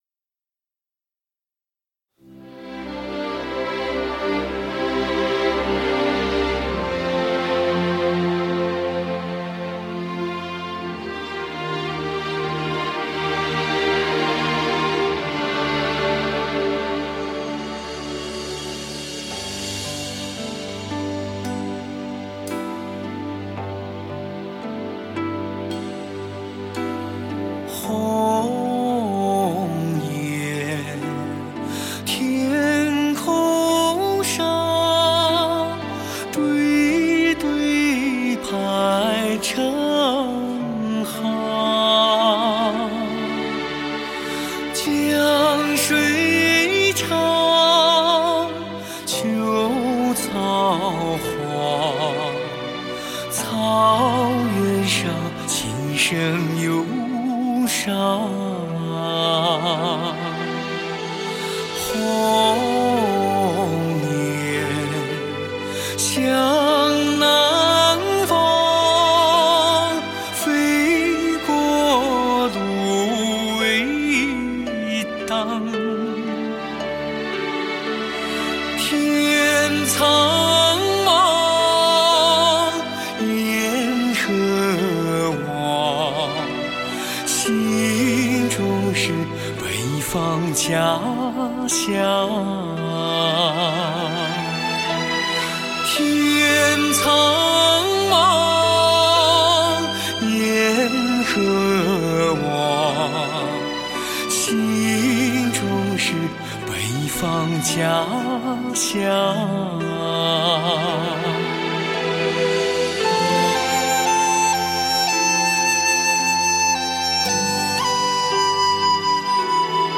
HIFI顶级人声测试天碟，精选十六首，顶级录音的天籁之音。